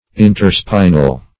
Search Result for " interspinal" : The Collaborative International Dictionary of English v.0.48: Interspinal \In`ter*spi"nal\, Interspinous \In`ter*spi"nous\, a. (Anat.) Between spines; esp., between the spinous processes of the vertebral column.